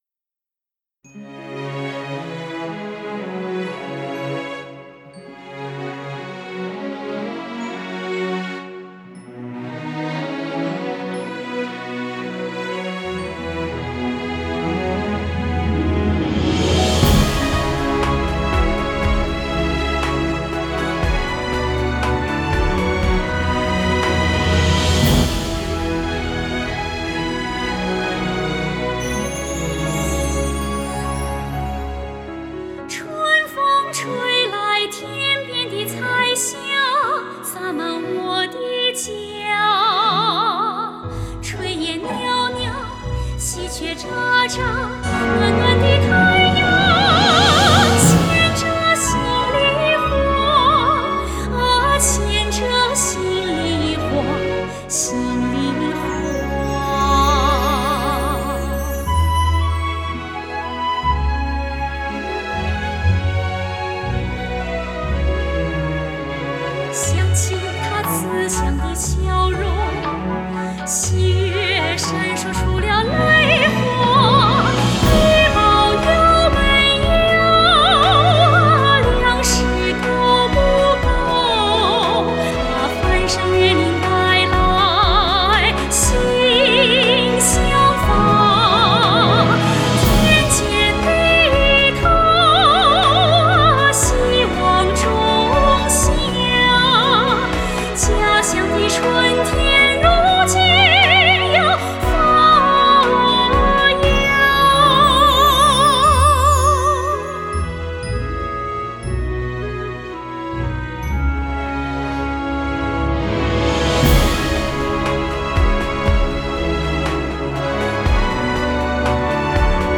曲谱完成后，得到了众多作曲人的认可和赞扬，认为旋律优美，深情温暖，层层递进，富有强烈的感染力，催人泪下。